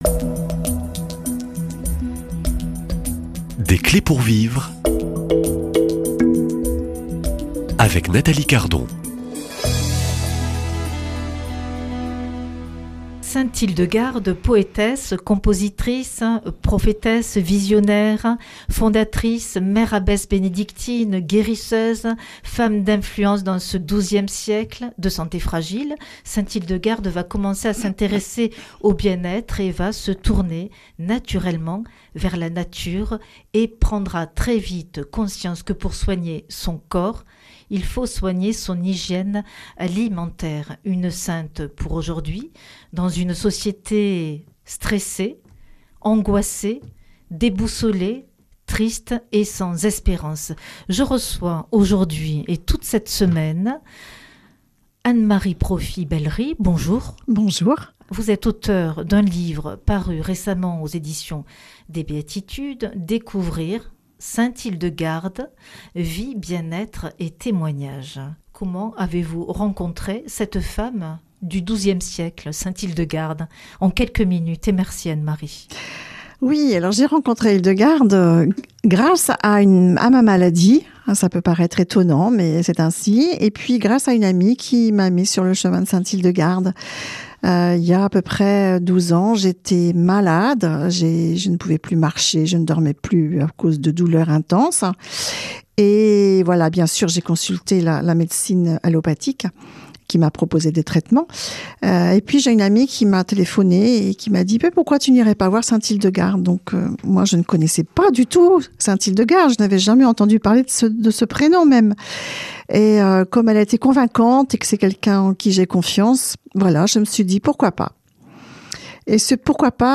Invitée